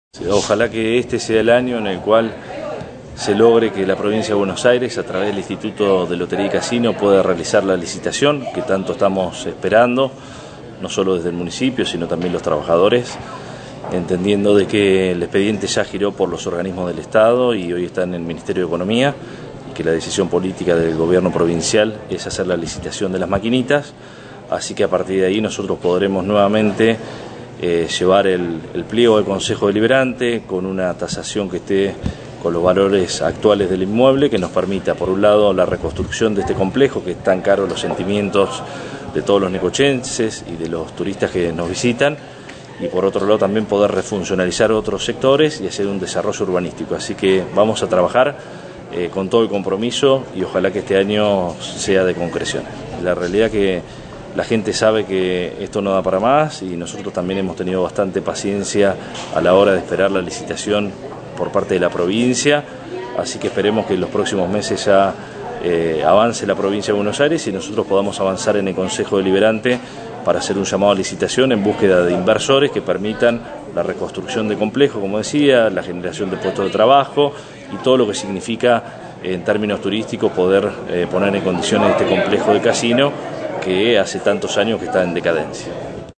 Al realizar declaraciones ante los medios de prensa, el Intendente anheló que «ojalá que este sea el año en el cual se logre que la Provincia de Buenos Aires a través del Instituto pueda realizar la licitación que tanto estamos esperando, no solo desde el municipio sino también los trabajadores, entendiendo que el expediente ya giró por los organismos del Estado y hoy está en el Ministerio de Economía».